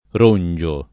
Rongio [ r 1 n J o ]